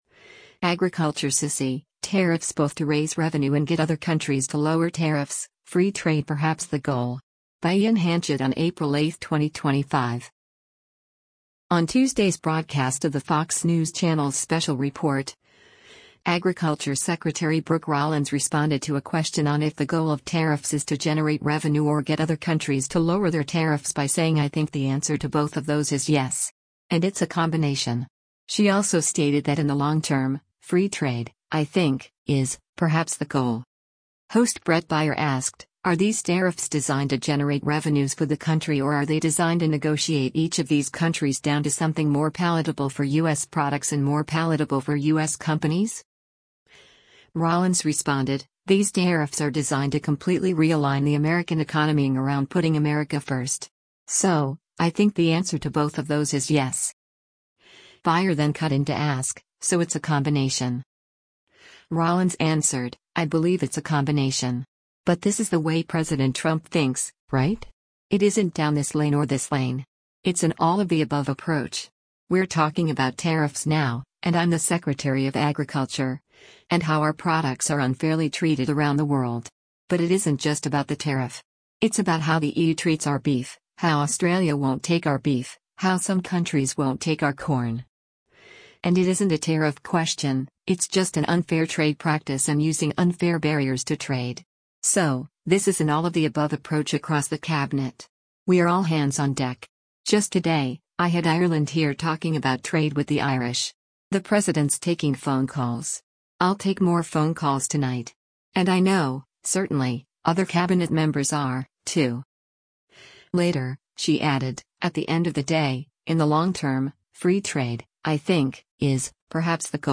On Tuesday’s broadcast of the Fox News Channel’s “Special Report,” Agriculture Secretary Brooke Rollins responded to a question on if the goal of tariffs is to generate revenue or get other countries to lower their tariffs by saying “I think the answer to both of those is yes.”